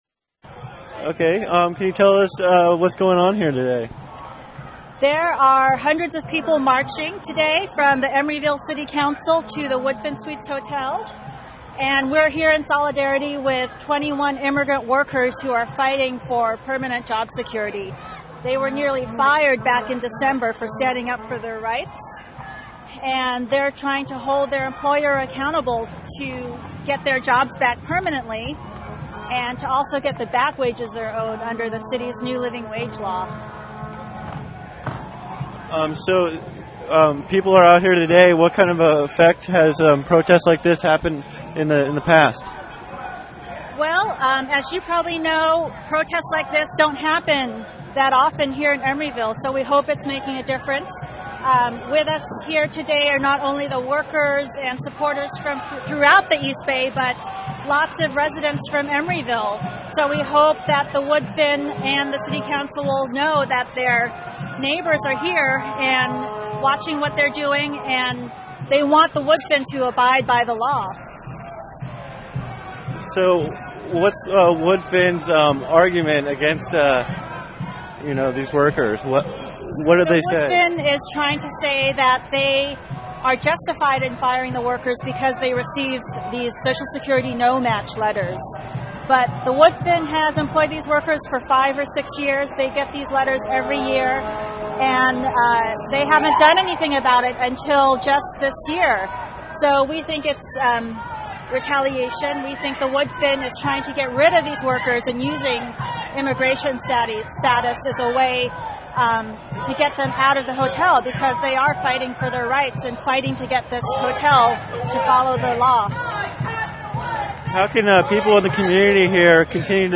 Woodfin workers march for Justice
Woodfin workers and people from the community marched for justice in the workplace. Workers are standing up for a living wage.